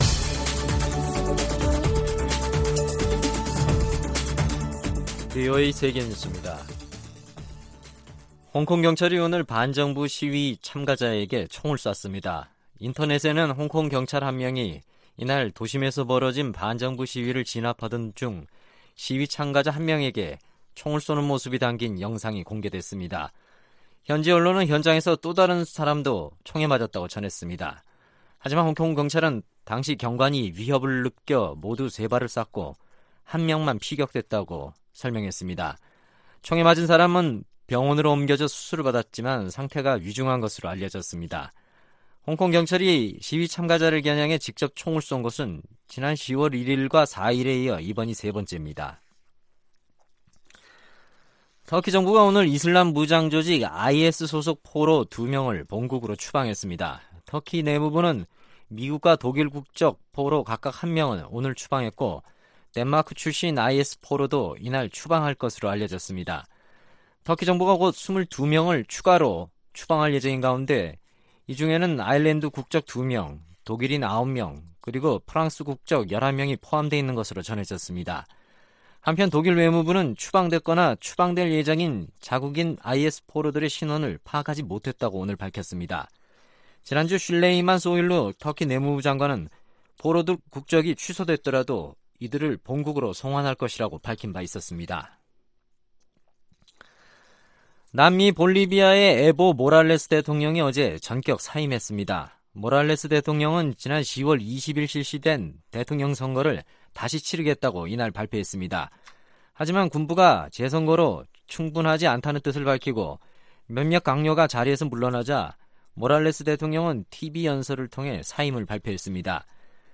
세계 뉴스와 함께 미국의 모든 것을 소개하는 '생방송 여기는 워싱턴입니다', 2019년 11월 11일 저녁 방송입니다. ‘지구촌 오늘’에서는 남미 볼리비아의 에보 모랄레스 대통령이 부정선거 의혹으로 전격 사임했다는 소식, ‘아메리카 나우’에서는 도널드 트럼프 대통령 탄핵 조사가 이번 주 공개 청문회로 전환된다는 이야기를 소개합니다. '구석구석 미국 이야기'에서는 재향군인들을 위한 양봉사업 이야기를 소개합니다.